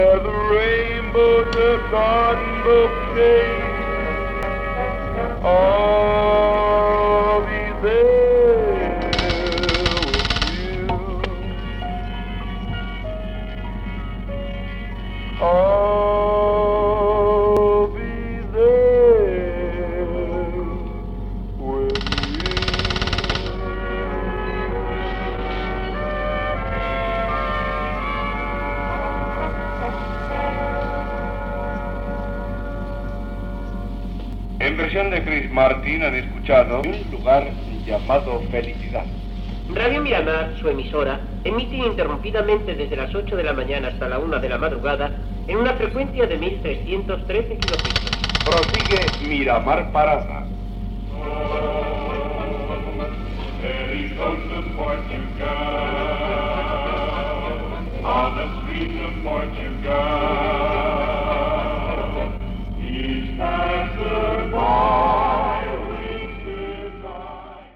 Musical
Enregistrament defectuós, amb alguns sorolls.